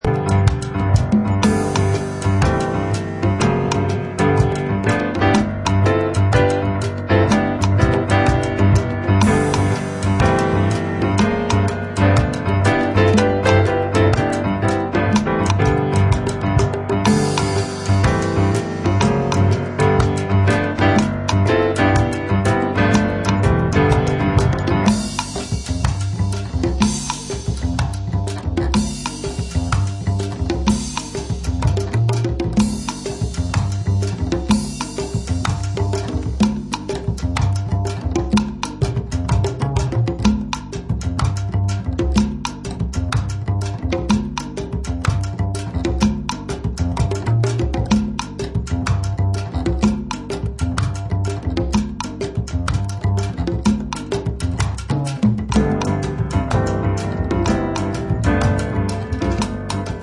pianist